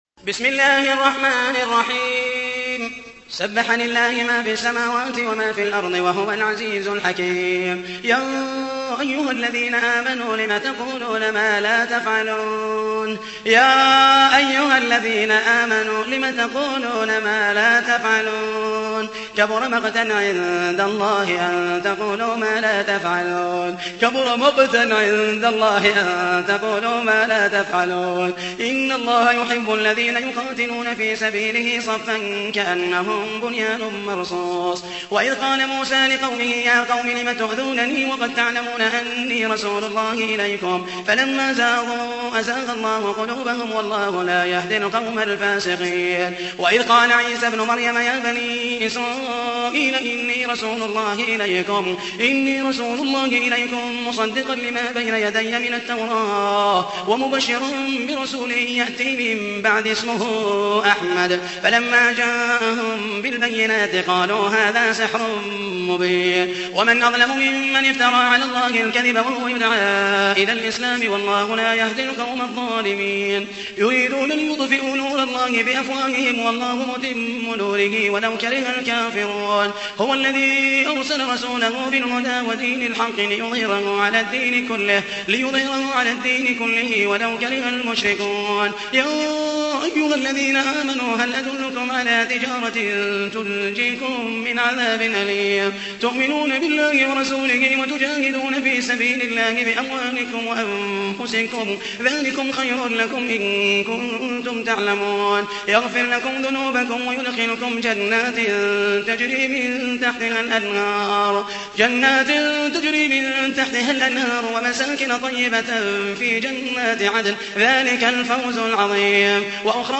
تحميل : 61. سورة الصف / القارئ محمد المحيسني / القرآن الكريم / موقع يا حسين